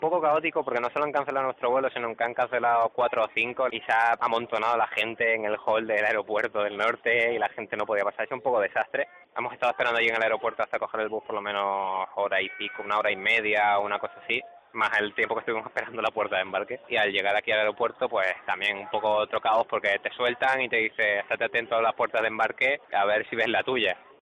Escucha a uno de los afectados por el cierre de Tenerife Sur